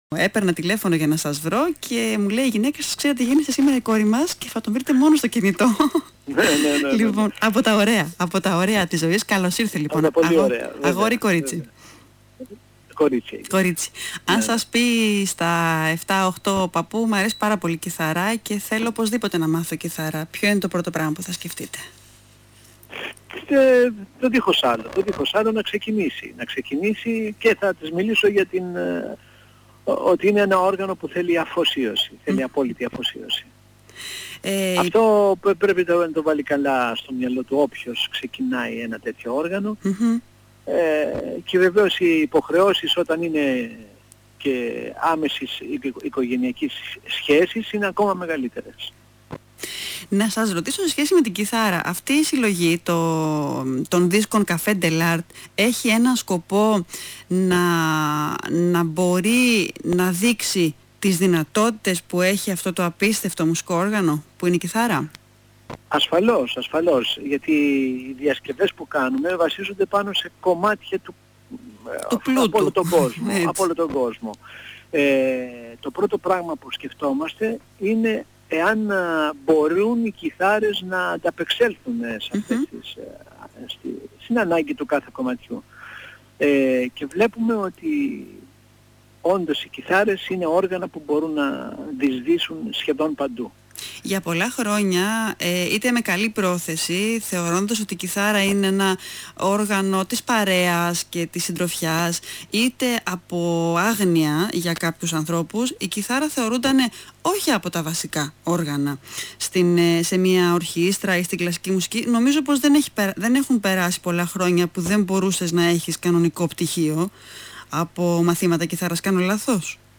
Συνέντευξη του Νότη Μαυρουδή